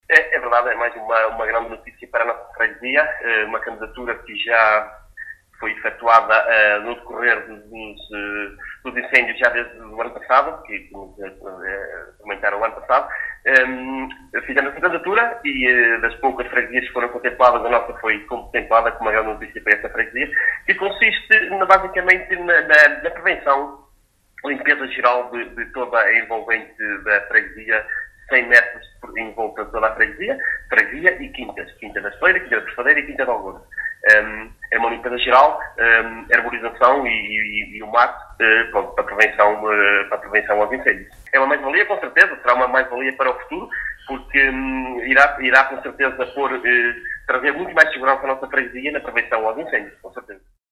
Jorge Cerdeira, Presidente da Junta de Freguesia de Pendilhe, em declarações à Alive FM, disse que é uma mais valia para a sua Freguesia, no sentido da limpeza e preservação da natureza, bem como da segurança da população.